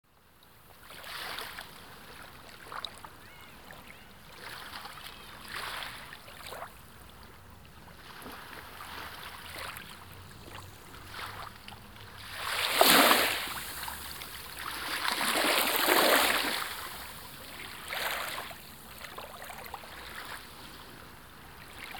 Звуки озера
Погрузитесь в атмосферу спокойствия с натуральными звуками озера: плеск воды, легкий ветерок, голоса водоплавающих птиц.
Шепот озера Байкал